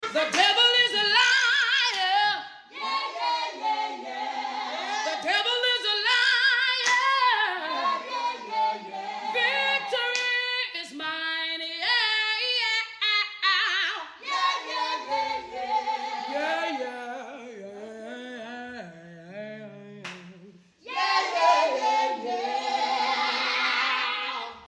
love the backup singer😂